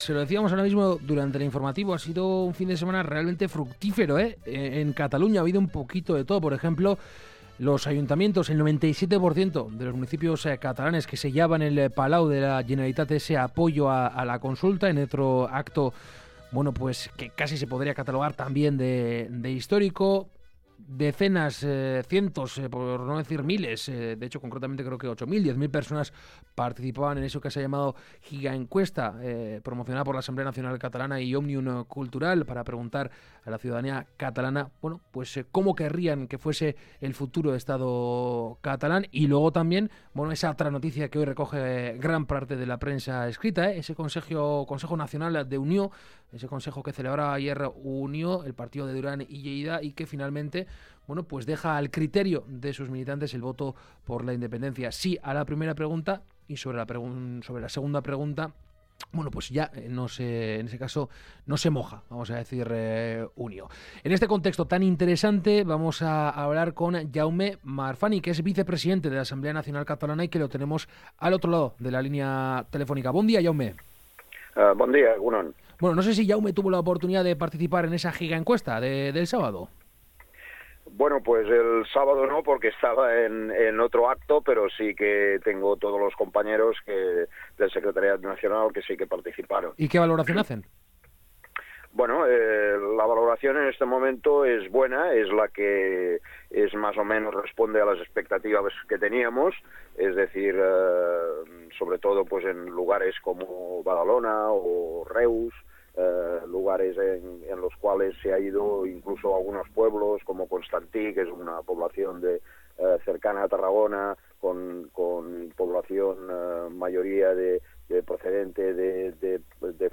Hoy en Kalegorrian hemos entrevistado